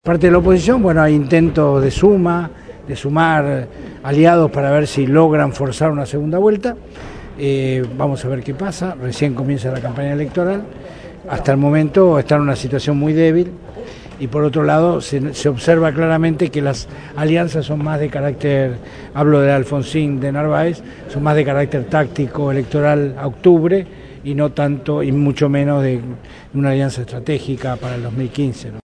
Producción y entrevista